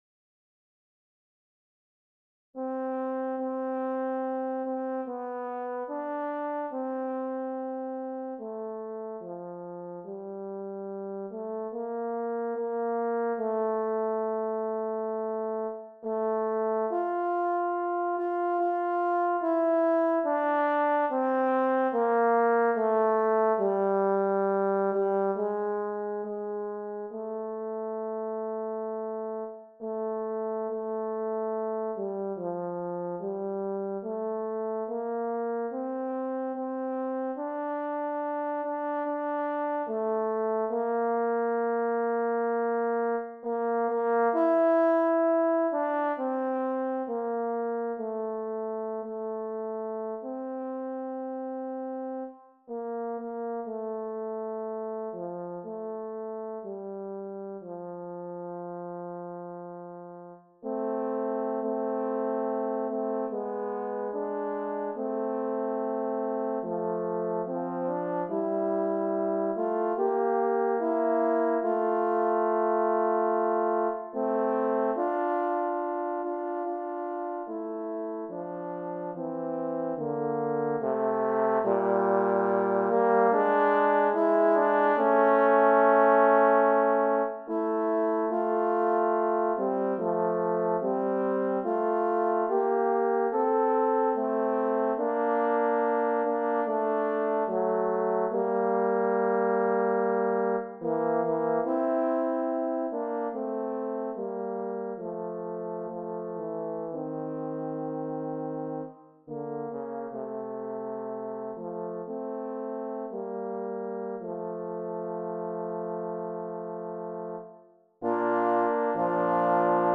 This is the hymn BENEATH THE CROSS OF JESUS, music originally by Maker that I have arranged for horn quartet.